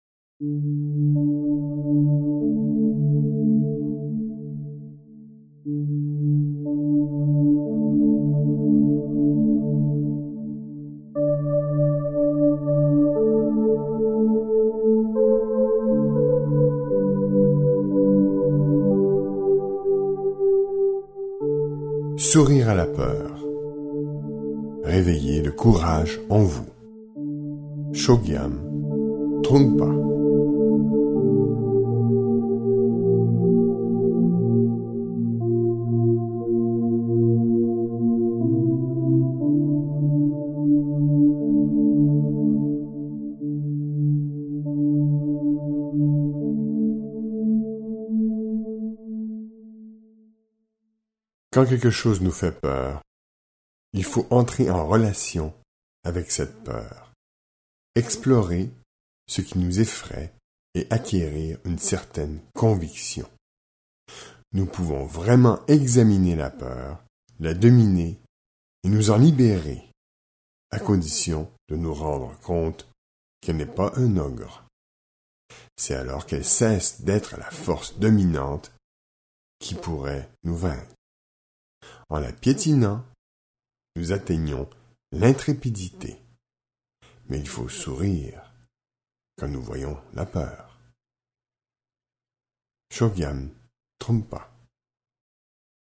Lire un extrait Chögyam Trungpa Sourire à la peur Réveillez le courage en vous Coffragants Date de publication : 2013 Un livre audio qui encourage à apprivoiser la peur pour ne plus la subir et prendre plaisir à vivre.